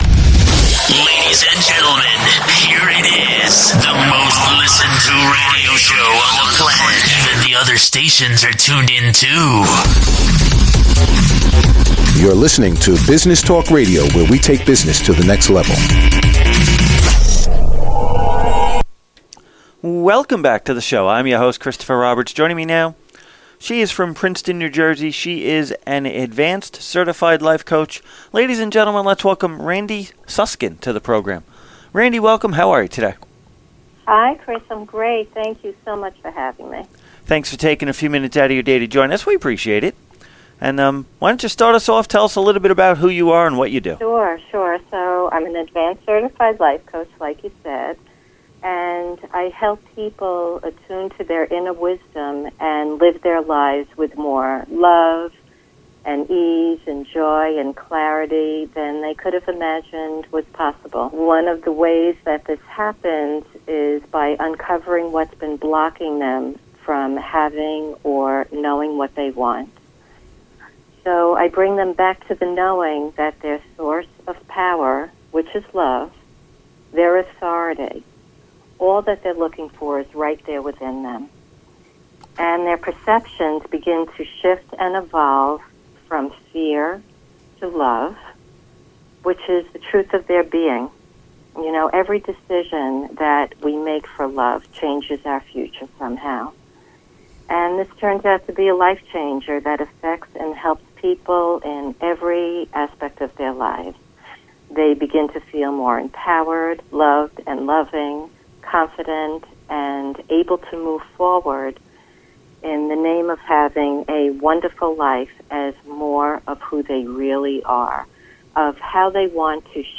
Business Talk Radio Interview